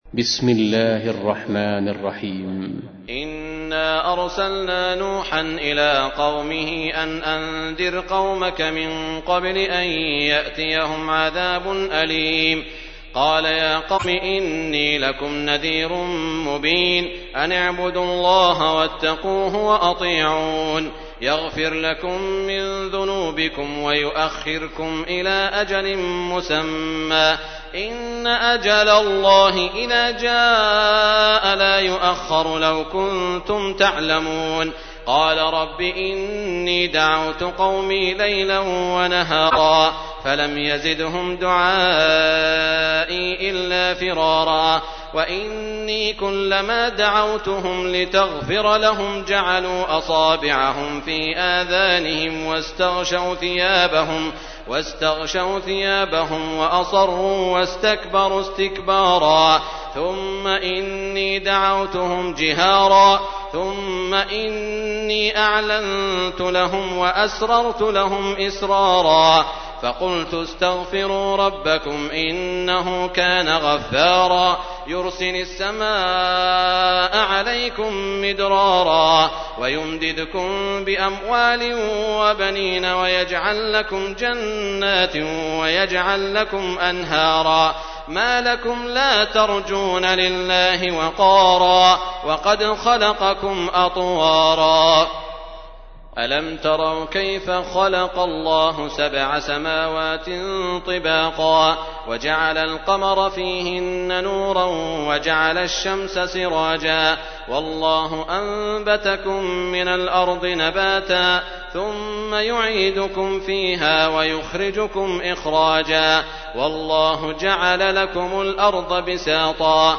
تحميل : 71. سورة نوح / القارئ سعود الشريم / القرآن الكريم / موقع يا حسين